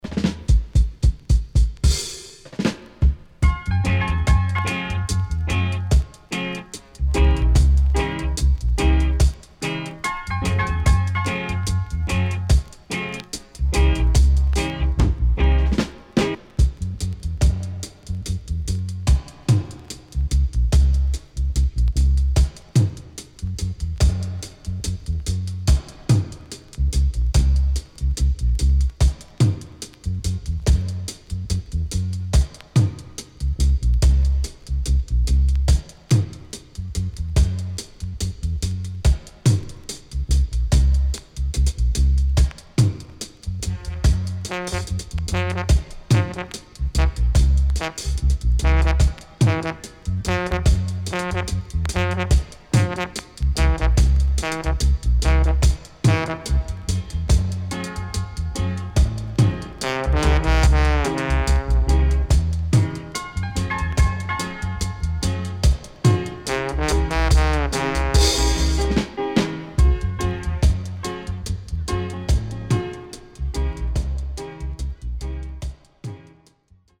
HOME > Back Order [VINTAGE 7inch]  >  KILLER & DEEP
75年 Female Killer Roots & Inst
SIDE A:所々チリノイズがあり、少しプチノイズ入ります。